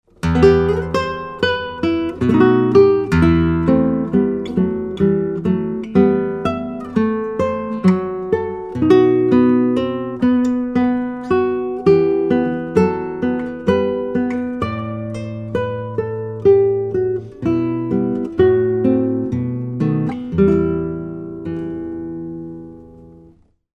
Torres FE - 17 style guitar
European Spruce soundboard, European Maple back & sides-